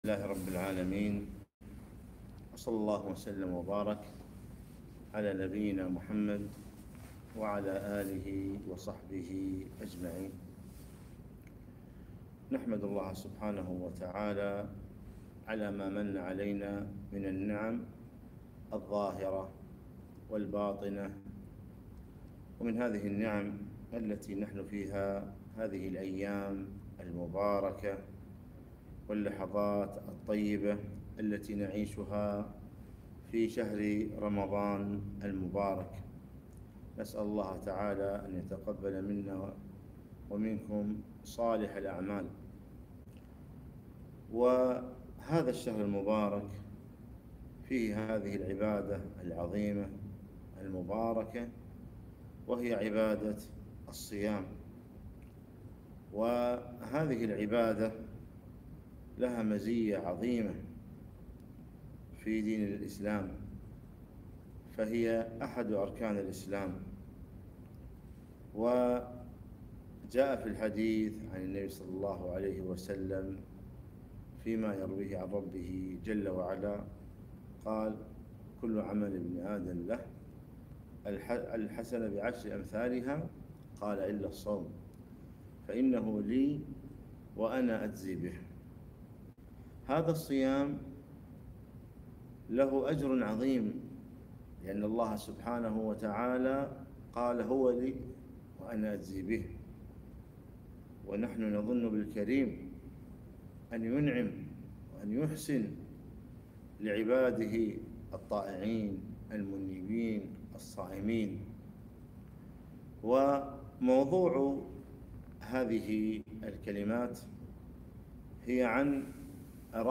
محاضرة - الصبر و الصيام